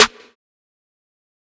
TC SNARE 14.wav